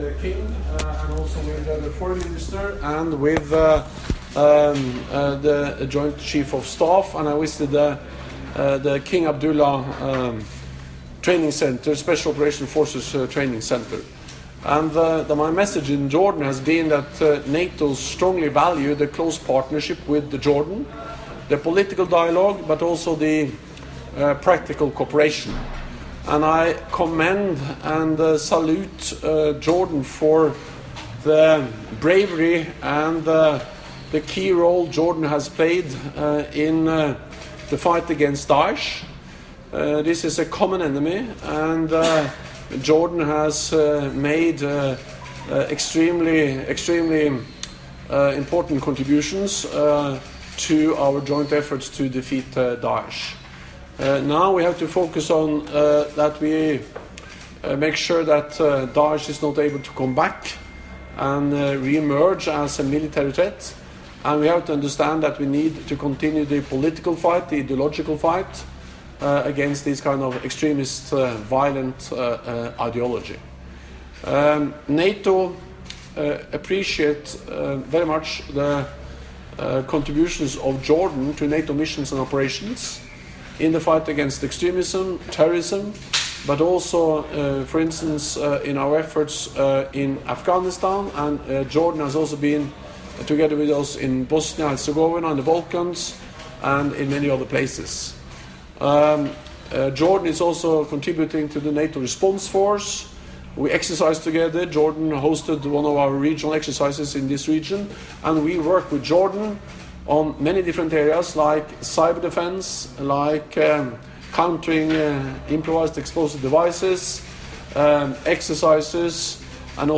Press point
by NATO Secretary General Jens Stoltenberg at the occasion of his visit to Jordan